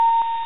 electronicpingshort.wav